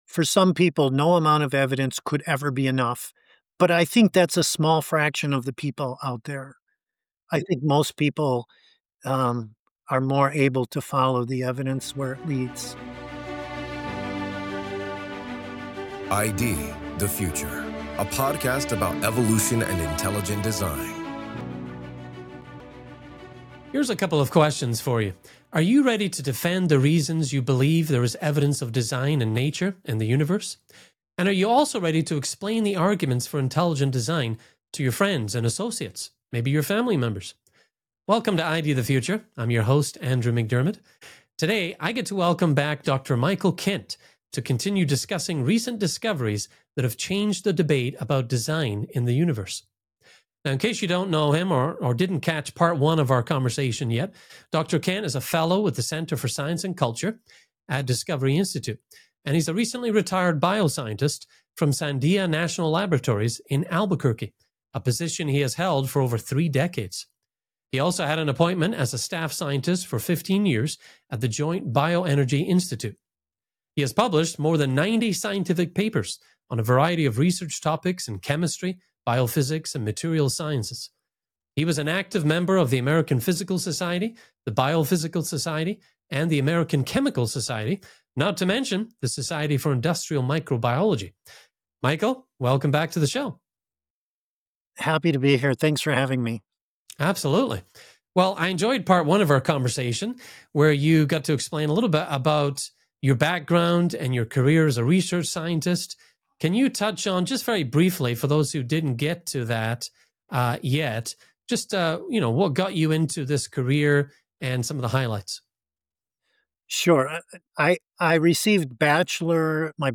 This is Part 2 of a two-part conversation.